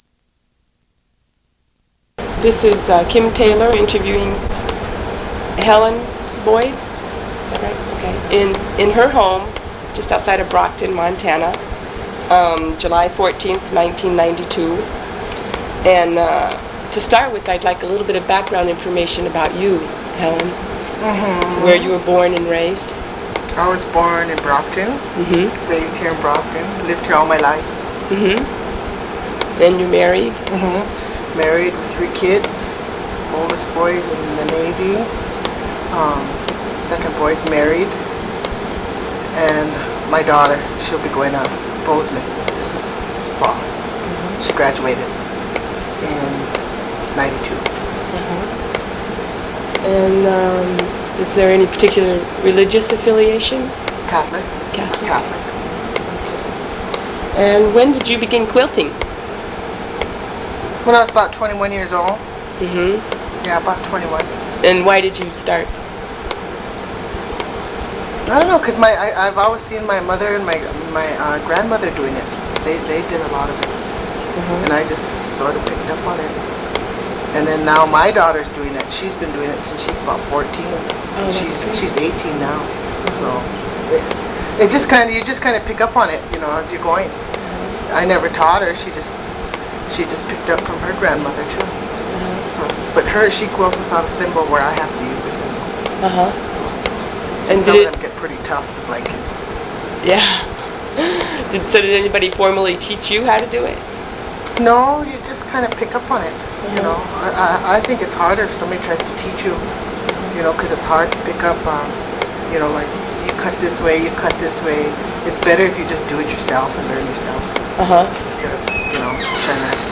Document Type Oral History
Original Format 1 sound cassette (00:33:28 min.) analog